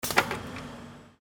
Gemafreie Sounds: Industrie
mf_SE-4115-paint_tin_clossing.mp3